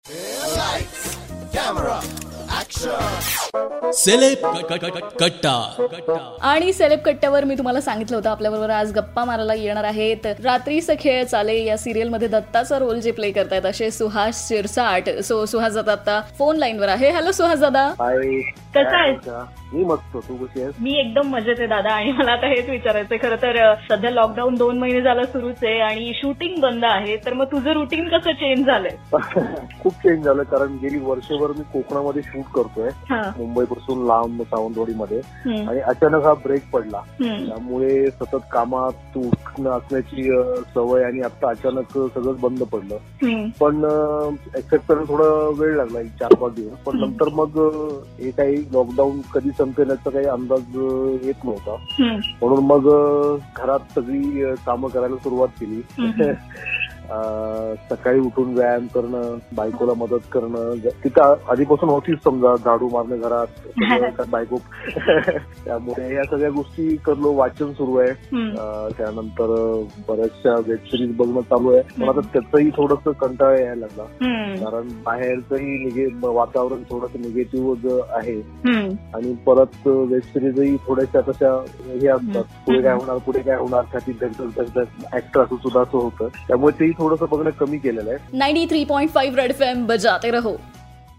In this interview he shared his routine in this lockdown situation..